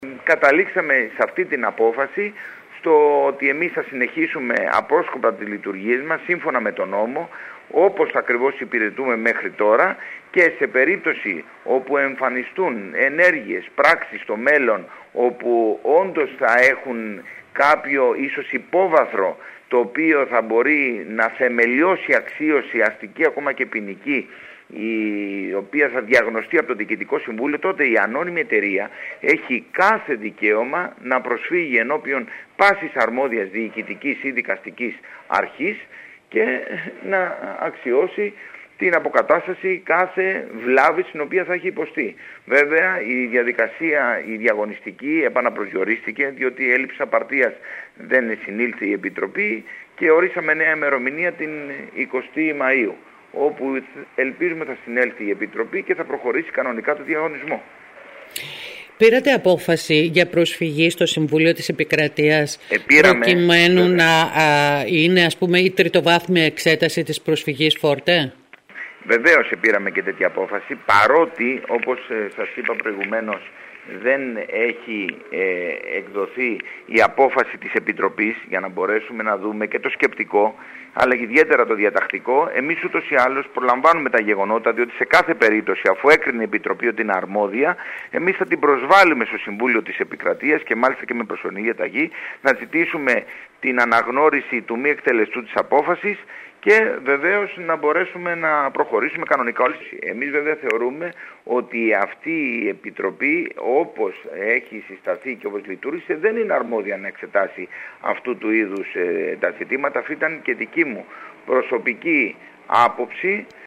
Σήμερα μιλώντας στην ΕΡΑ ΚΕΡΚΥΡΑΣ ο αντιπεριφερειάρχης και αντιπρόεδρος του Διαβαθμιδικού φορέα Σπύρος Ιωάννου δήλωσε ότι παράλληλα θα ζητηθεί από το ΣτΕ προσωρινή διαταγή για την αναγνώριση της νομιμότητας των πράξεων και αποφάσεων που έχει λάβει μέχρι τώρα ο Διαβαθμιδικός φορέας.